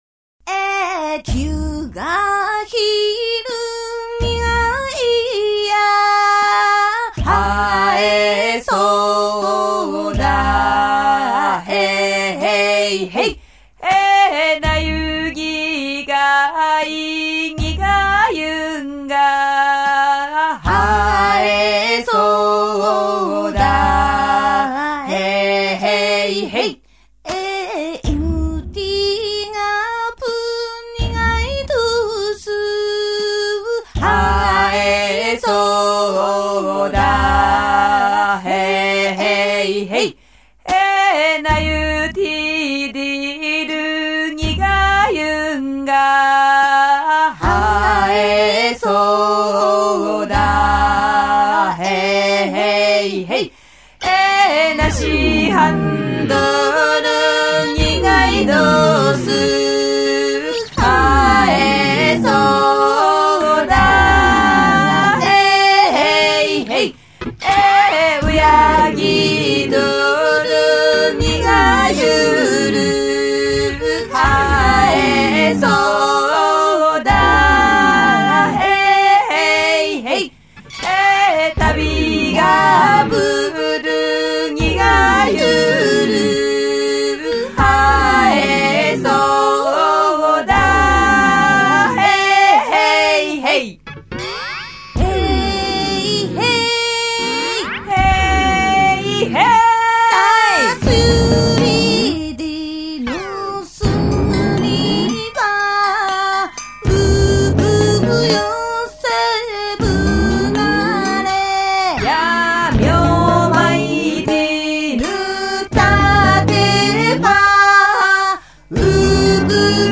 音乐类型：世界音乐
主唱、三线、太鼓、三板
电吉他、打击乐器